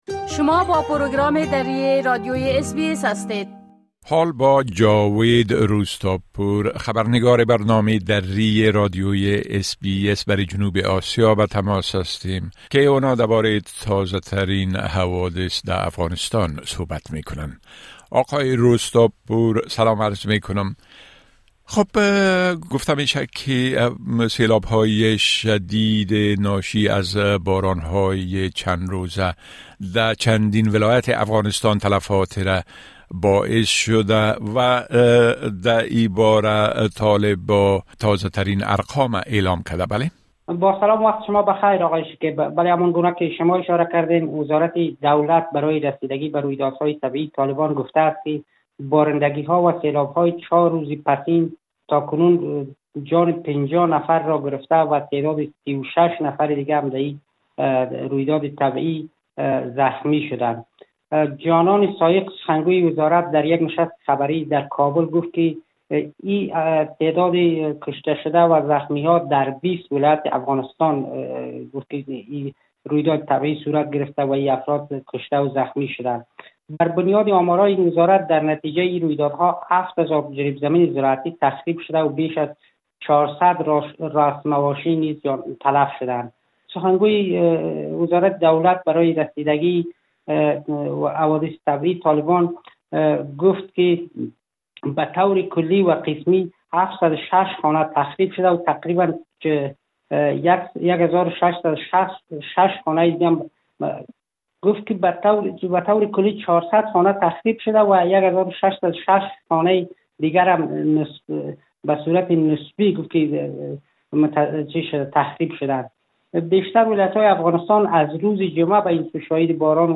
خبرنگار ما برای جنوب آسیا: شمار كشته شده ها در اثر سيلابها در چند ولايت افغانستان افزايش يافته است